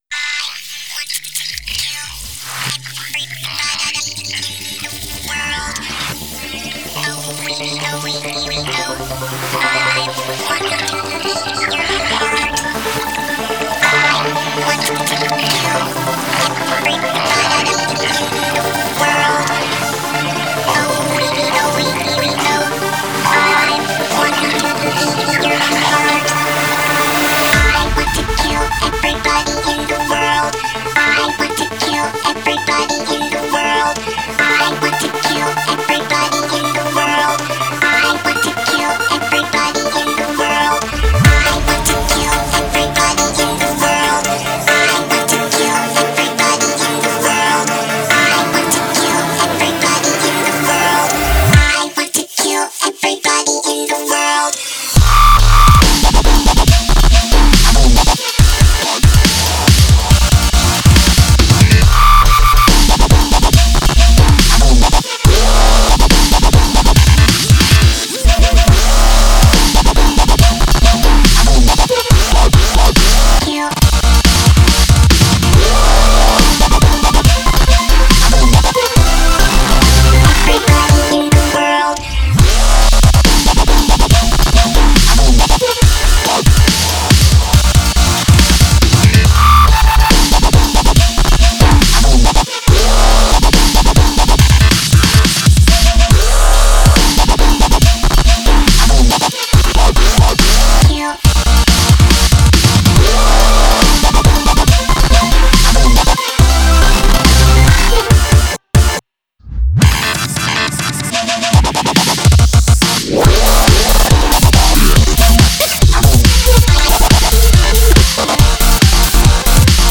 (braindamage dubstep)